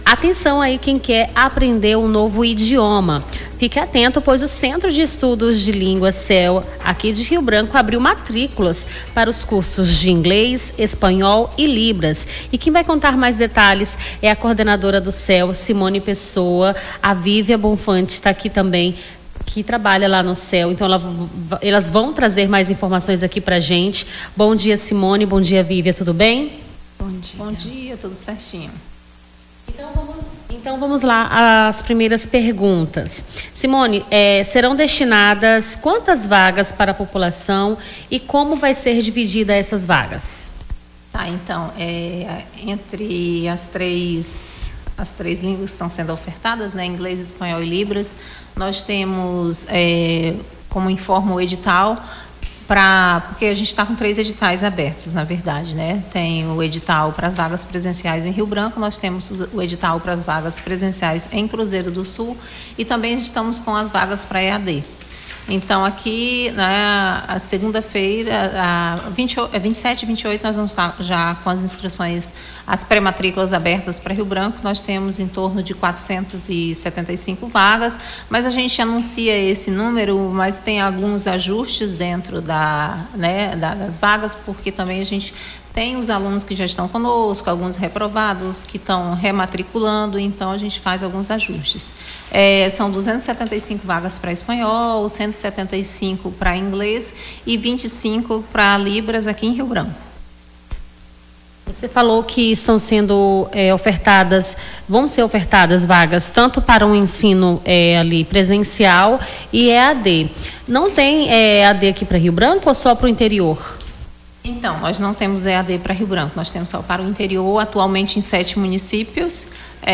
Nome do Artista - CENSURA - ENTREVISTA CEL MATRÍCULAS (24-01-25).mp3